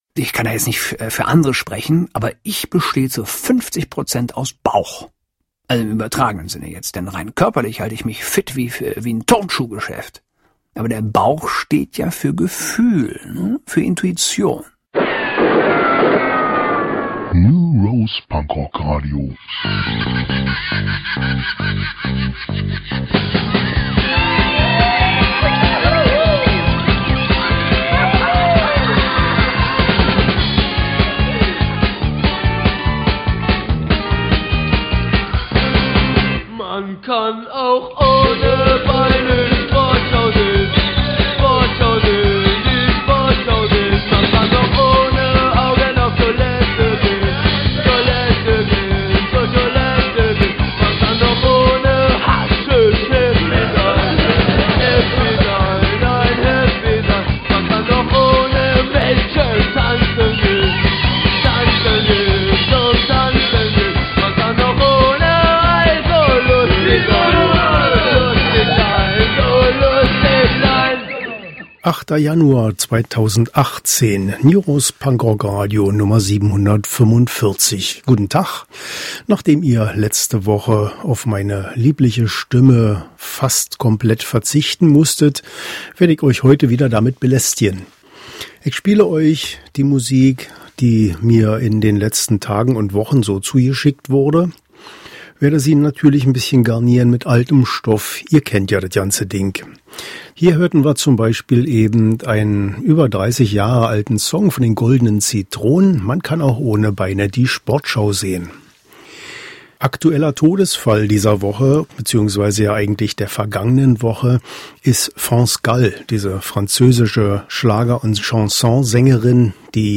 Und weiter geht’s mit Neu-Eingängen und so…heute wieder mit Stimme!